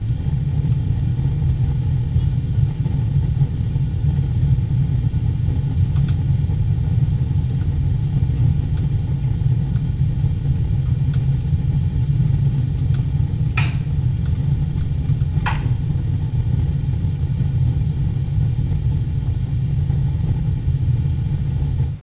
boiler.wav